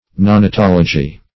neonatology - definition of neonatology - synonyms, pronunciation, spelling from Free Dictionary